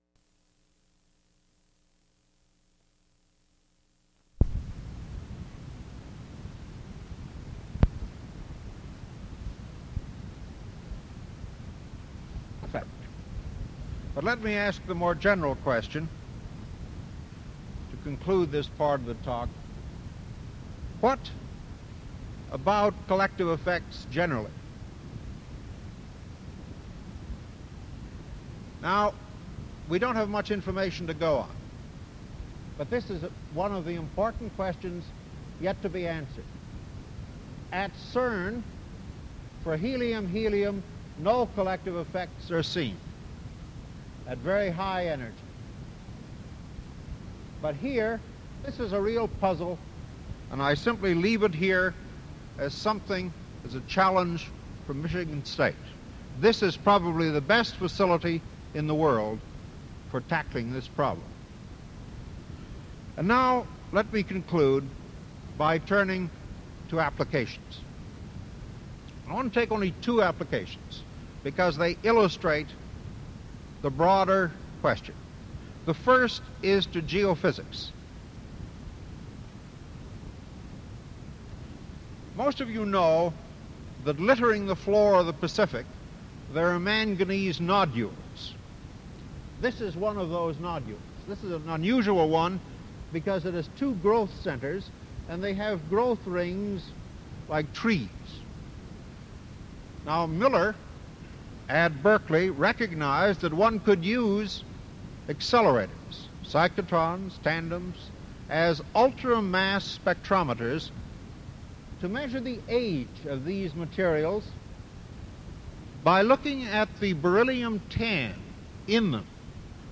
Audio/mp3 Original Format: Audiocassettes Resource Identifier
Sesquicentennial Oral History Project